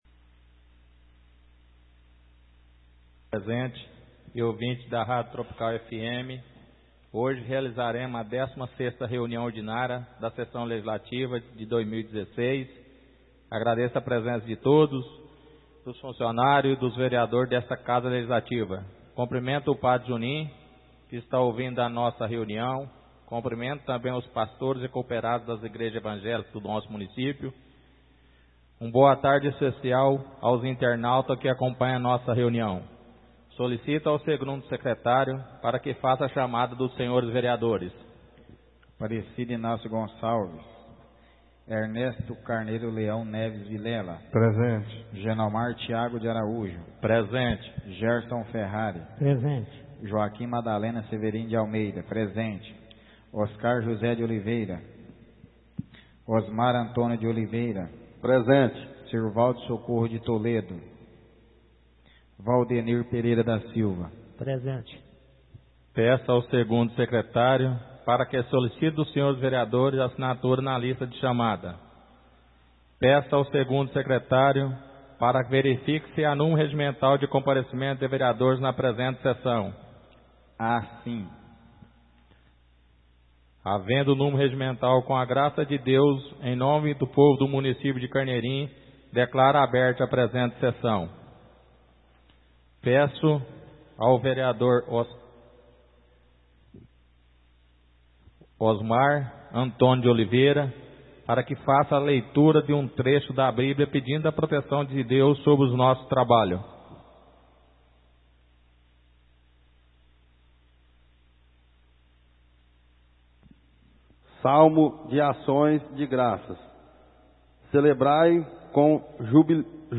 Áudio da 16ª reunião ordinária de 2016, realizada no dia 17 de Outubro de 2016, na sala de sessões da Câmara Municipal de Carneirinho, Estado de Minas Gerais.